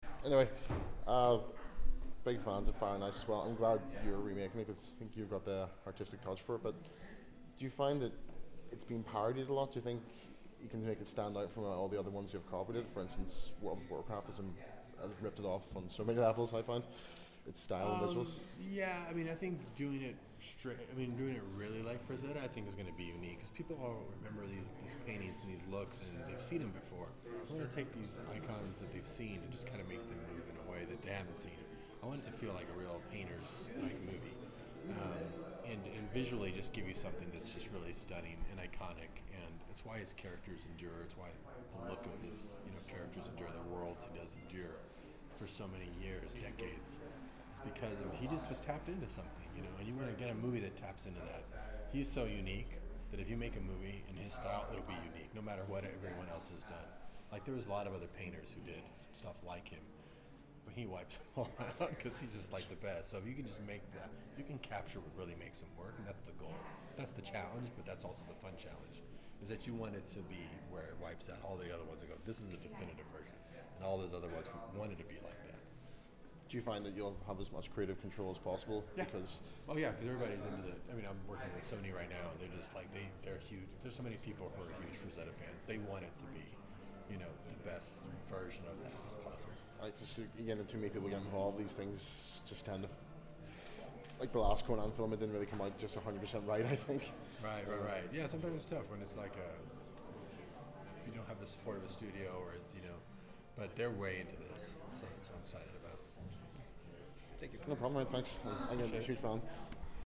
Interview with Director Robert Rodriguez at 2015 SXSW Film Festival
After the tour we had the opportunity to ask Robert Rodriguez a few questions.
robert-rodriguez-interview.mp3